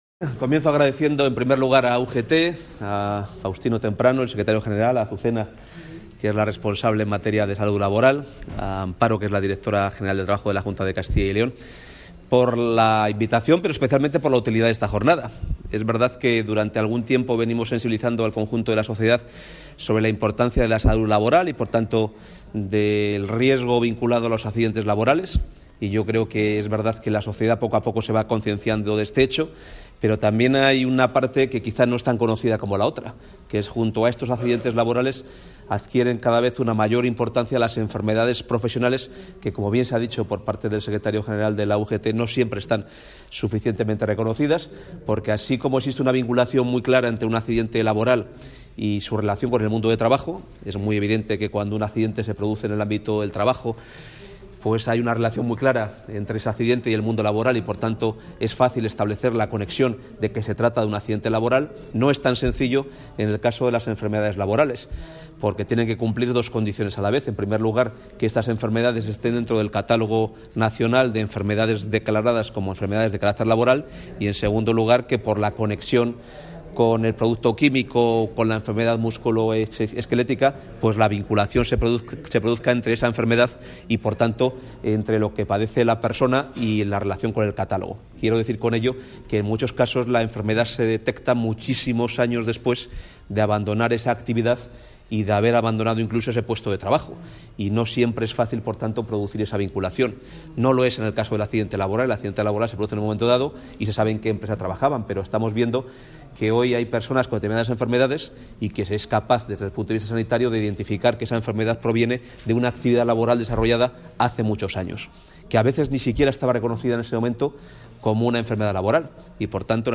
Declaraciones del consejero de Empleo en la clausura de una jornada sobre enfermedades profesionales
Declaraciones del consejero de Empleo en la clausura de una jornada sobre enfermedades profesionales Contactar Escuchar 13 de diciembre de 2018 Castilla y León | El consejero de Empleo, Carlos Fdez. Carriedo, ha intervenido hoy en Valladolid en la clausura de la jornada 'Las Enfermedades Profesionales a debate: desafíos de futuro', organizada por UGT.